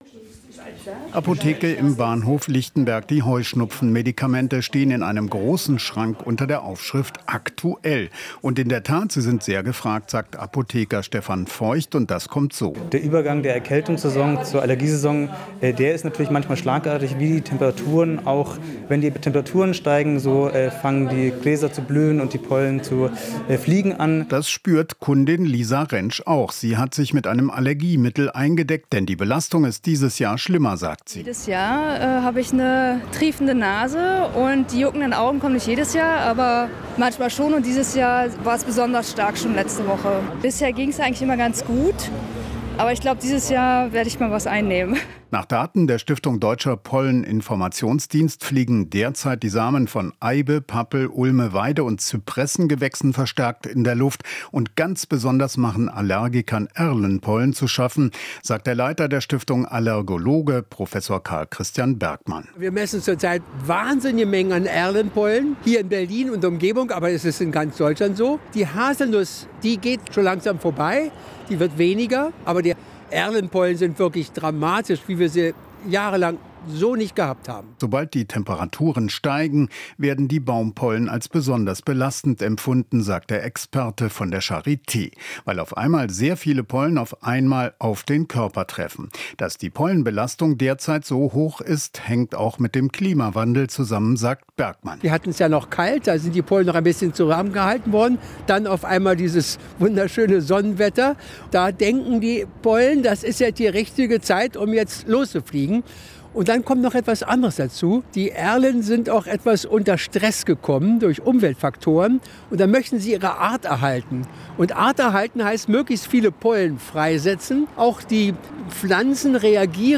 Alles was wichtig ist in der Hauptstadtregion - in Interviews, Berichten und Reportagen.